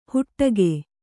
♪ huṭṭage